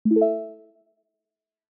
notification-sound.mp3